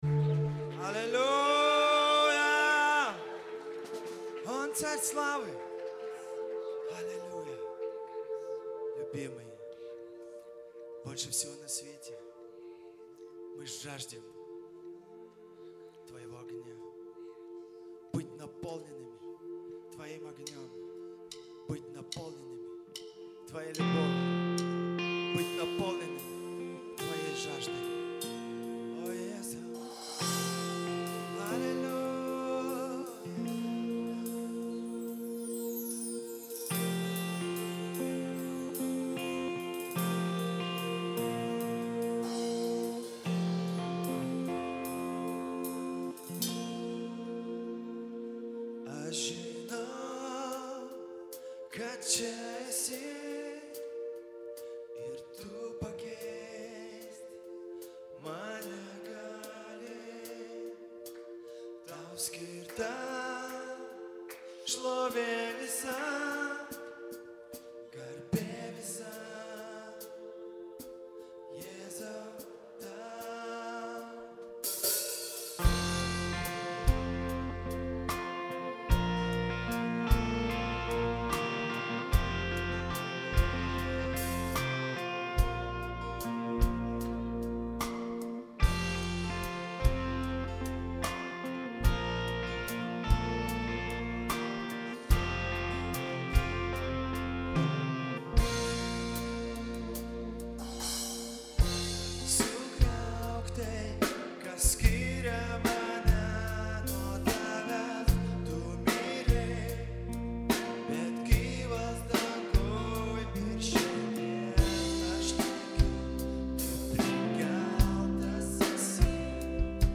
Worship TCGVS 2011